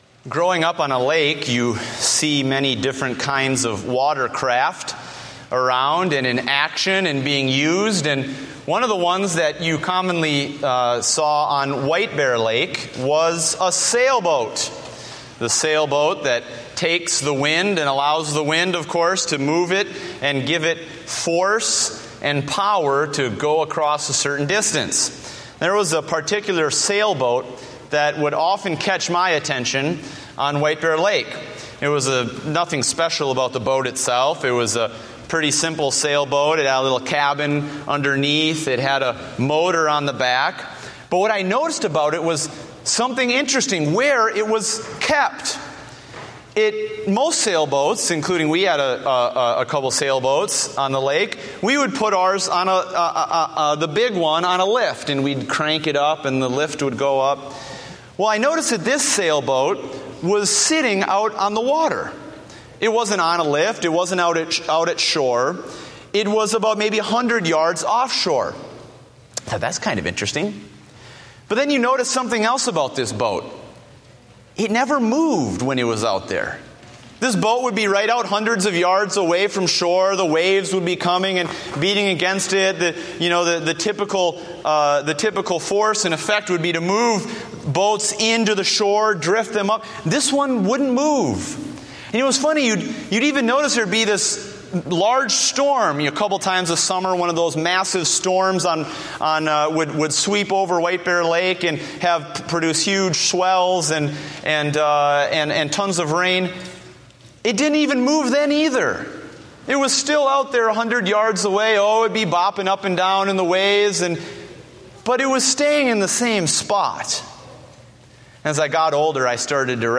Date: August 3, 2014 (Morning Service)